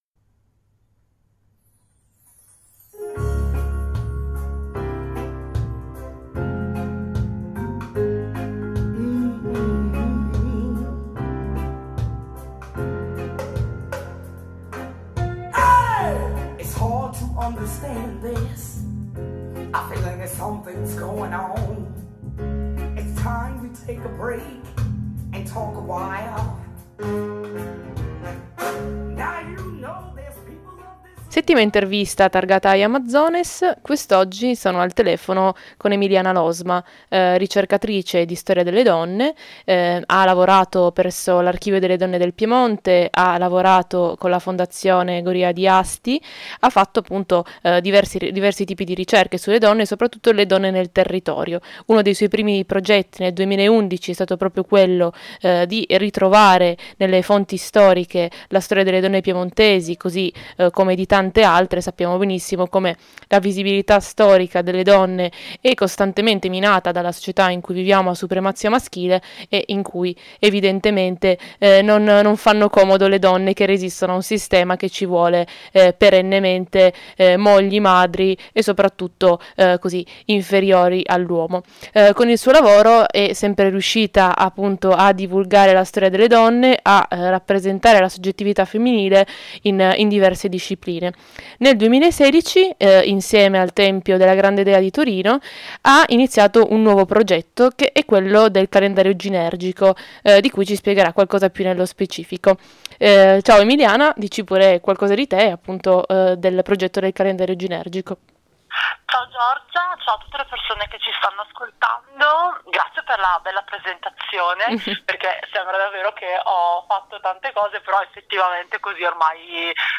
In questa settima intervista targata Ai Amazones